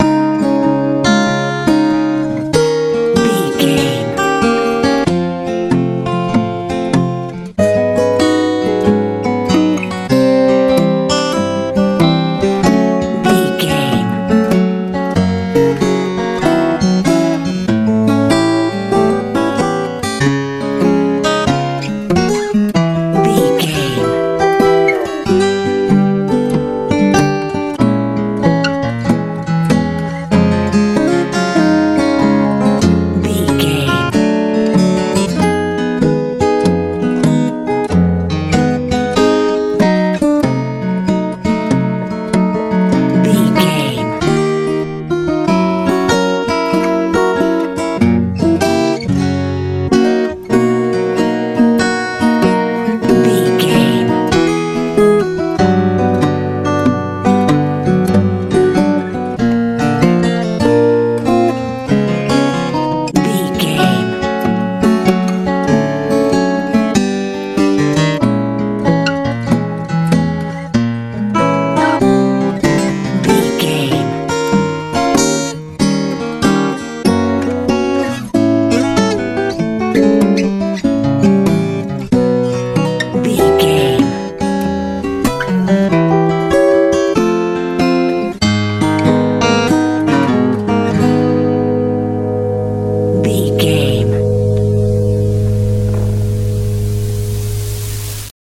nashville style feel
Ionian/Major
C♯
light
acoustic guitar
sweet
mellow
bright